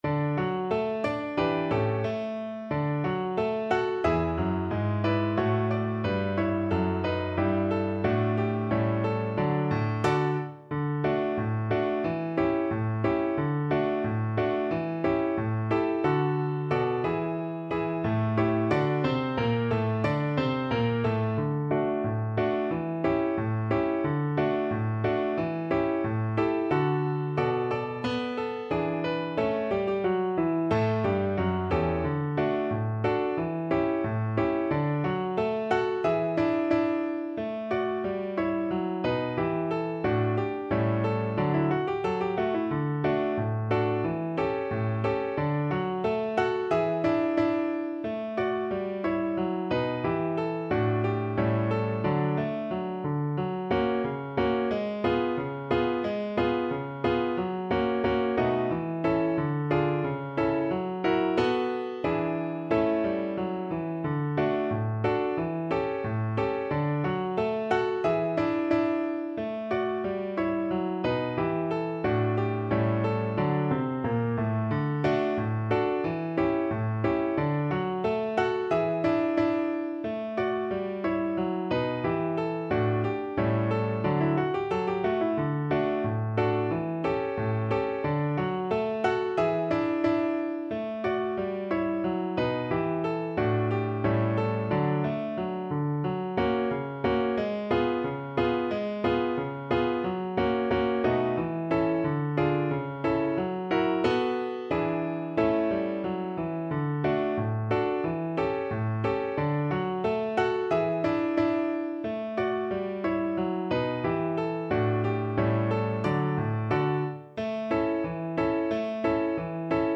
2/2 (View more 2/2 Music)
=90 Fast and cheerful